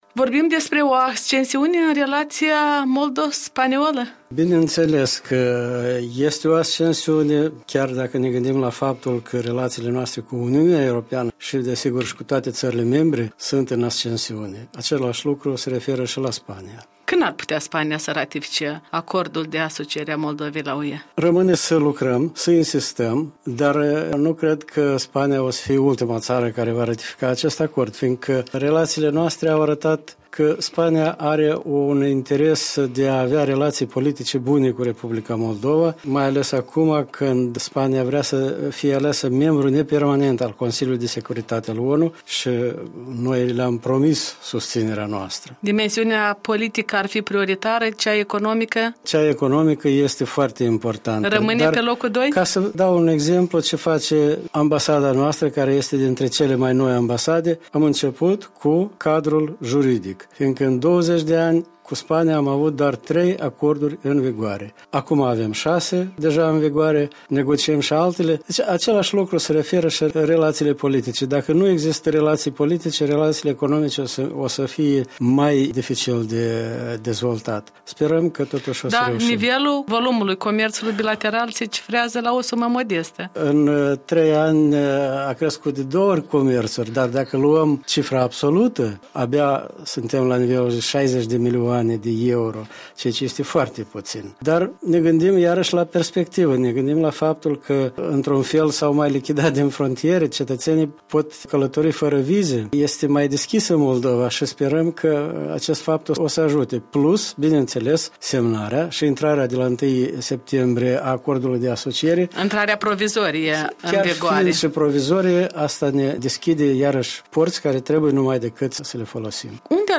Un interviu cu Valeriu Gheorghiu, ambasadorul R. Moldova la Madrid